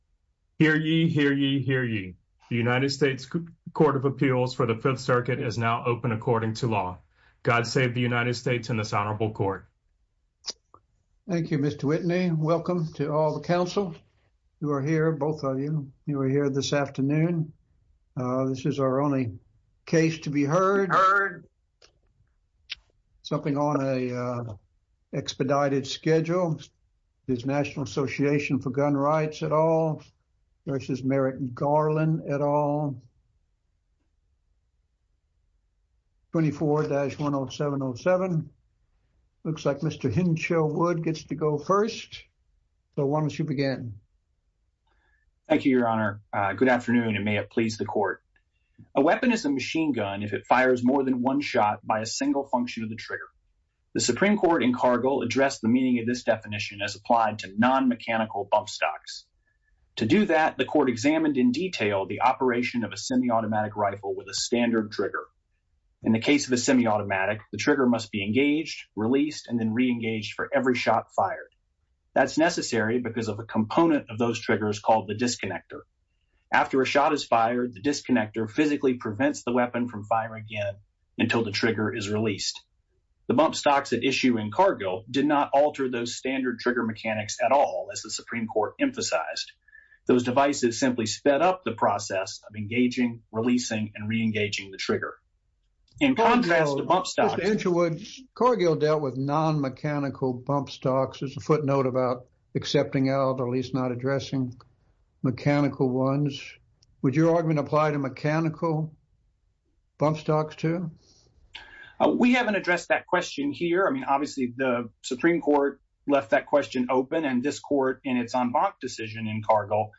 A chronological podcast of oral arguments with improved files and meta data.